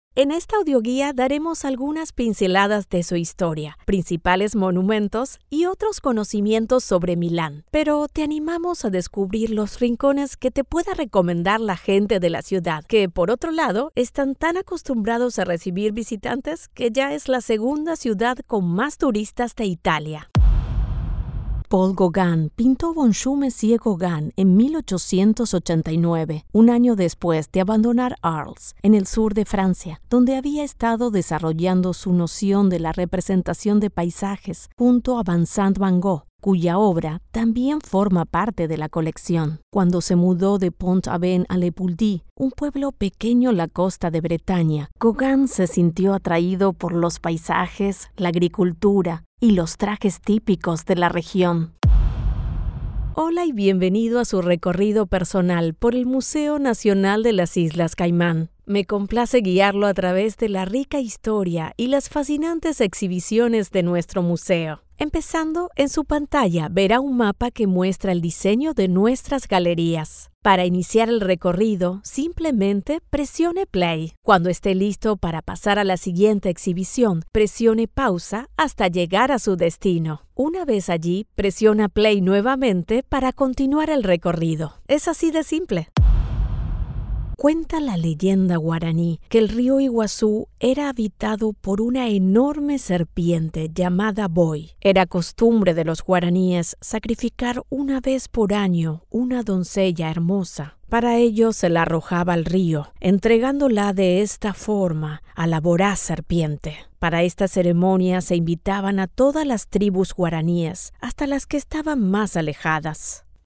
Cálida, Profundo, Natural, Versátil, Empresarial, Joven, Cool, Accesible, Amable, Suave
Audioguía
Her voice has been described as warm, friendly, casual, smooth and sensual, depending on the projects.